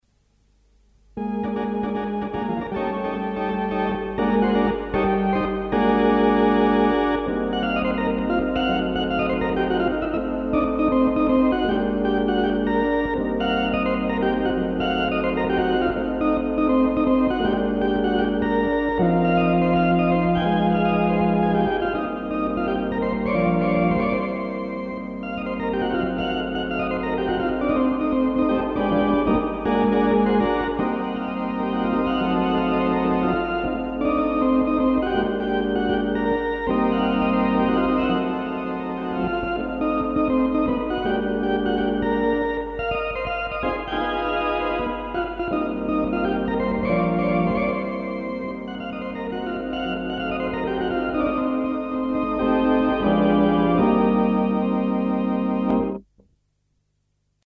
Philicorda
As the recording bug was already well installed (audio only in those days!) a few tape records were made – some of which have survived the intervening years and multiple shifts in technology and media standards.
philicorda_kh_lively2.mp3